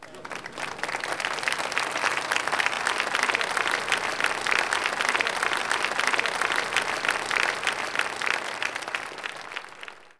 clap_025.wav